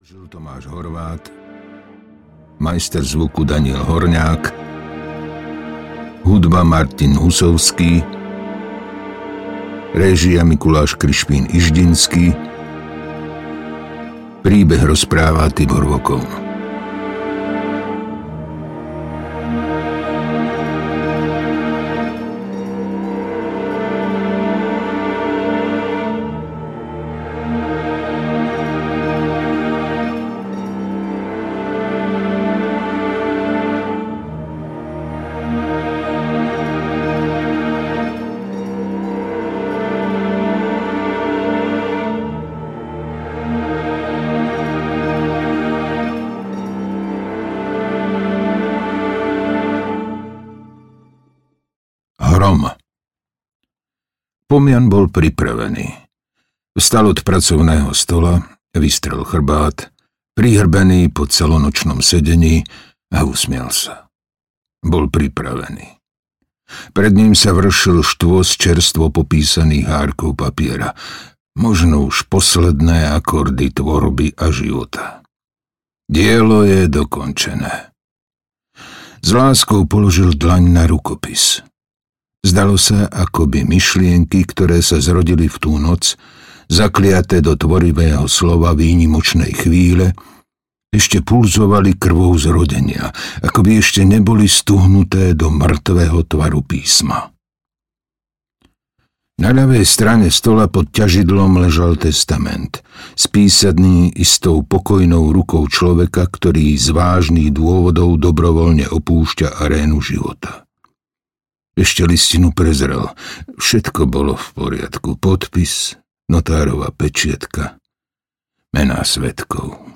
Tieň Bafometa audiokniha
Ukázka z knihy